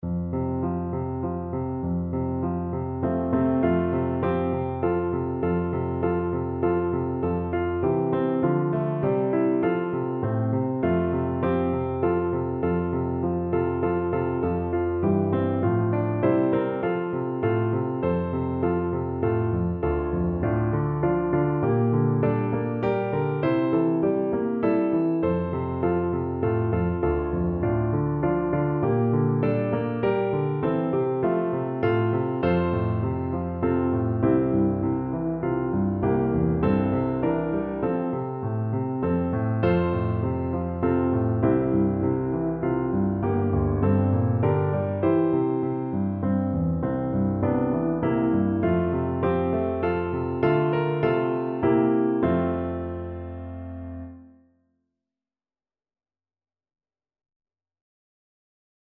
No parts available for this pieces as it is for solo piano.
F major (Sounding Pitch) (View more F major Music for Piano )
3/4 (View more 3/4 Music)
Moderato
Piano  (View more Intermediate Piano Music)
Traditional (View more Traditional Piano Music)